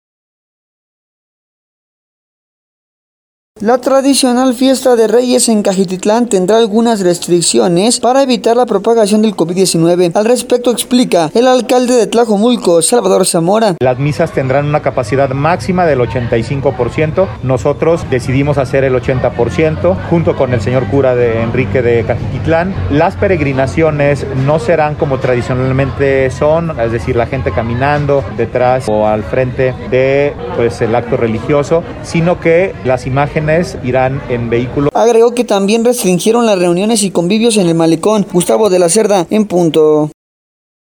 La tradicional fiesta de reyes en Cajititlán, tendrá algunas restricciones para evitar la propagación del Covid-19, al respecto explica el alcalde de Tlajomulco, Salvador Zamora: